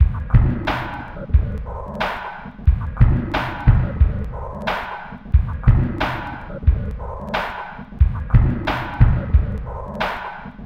标签： 90 bpm Weird Loops Drum Loops 1.79 MB wav Key : Unknown
声道立体声